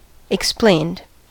explained: Wikimedia Commons US English Pronunciations
En-us-explained.WAV